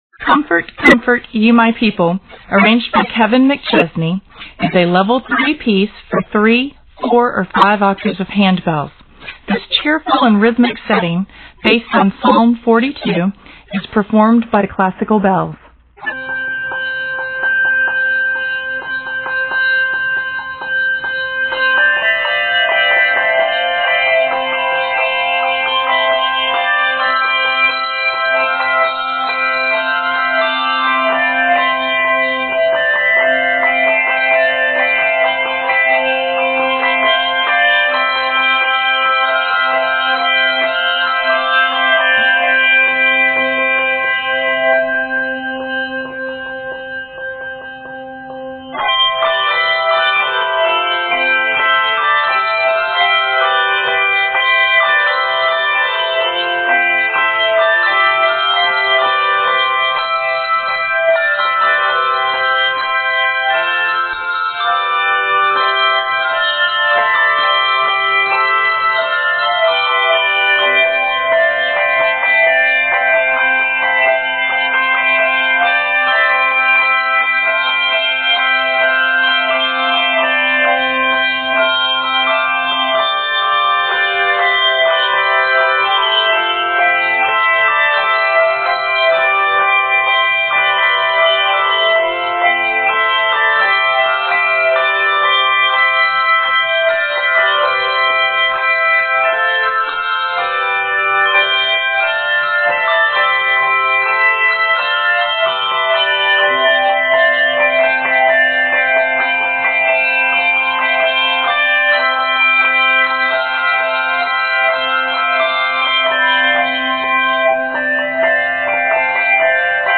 Octaves: 3-5